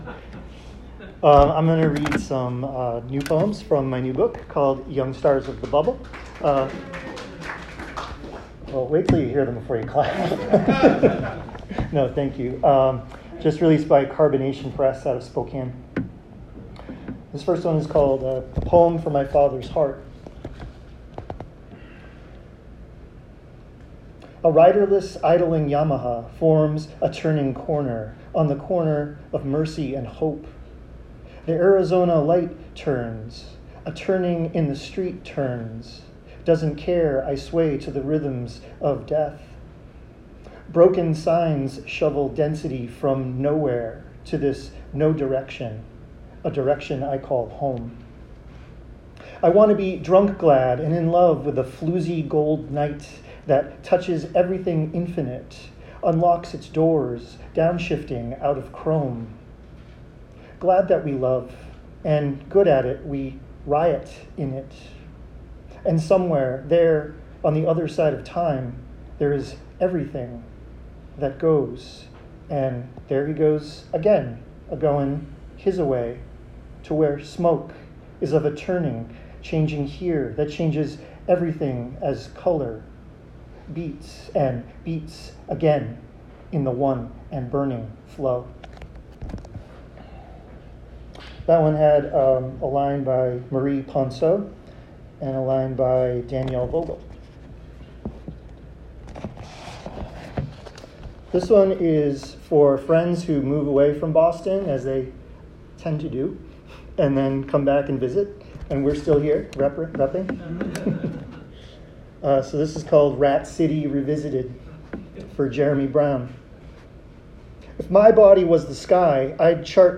readings
Boston Poetry Marathon 2025
Community Church of Boston | Boston, MA
boston-poetry-marathon-reading-2025.m4a